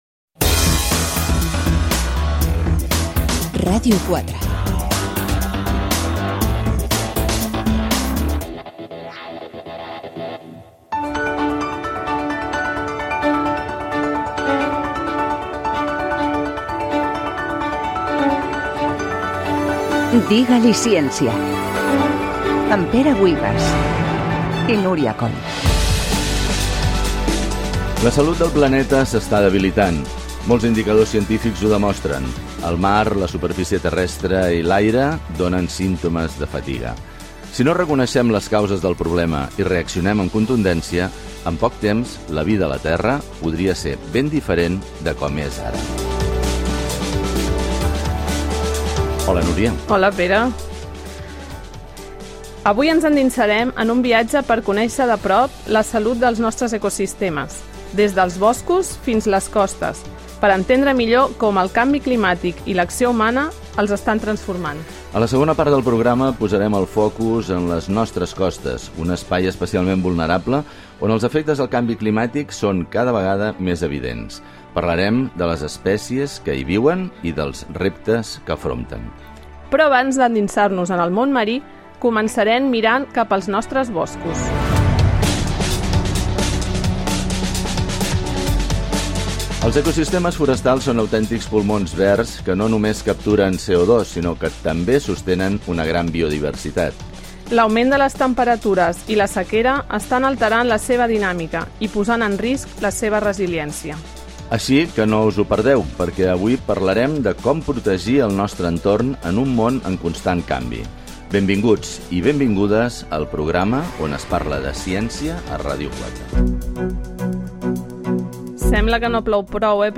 Indicatiu de la ràdio, careta, sumari de continguts, la gestió dels boscos i la sequera